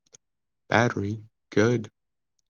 battery-good.wav